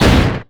IMPACT_Generic_04_mono.wav